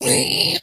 Minecraft Version Minecraft Version snapshot Latest Release | Latest Snapshot snapshot / assets / minecraft / sounds / mob / zombified_piglin / zpighurt1.ogg Compare With Compare With Latest Release | Latest Snapshot
zpighurt1.ogg